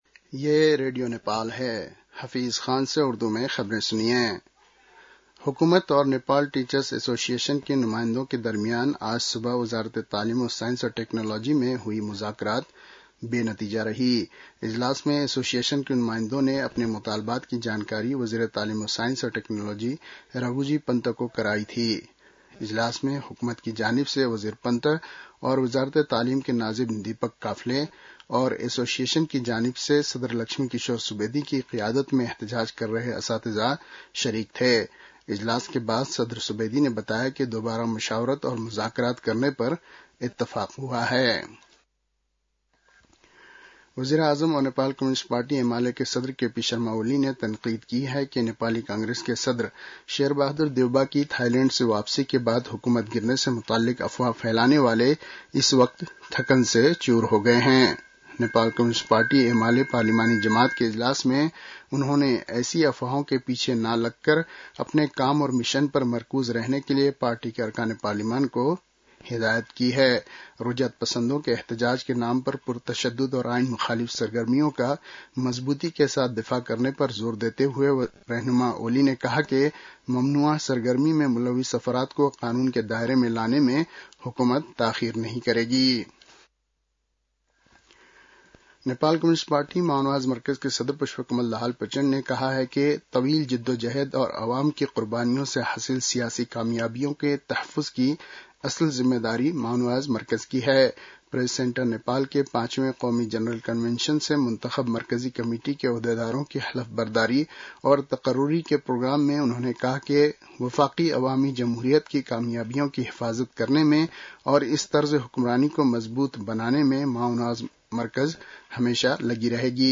उर्दु भाषामा समाचार : १३ वैशाख , २०८२